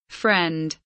friend kelimesinin anlamı, resimli anlatımı ve sesli okunuşu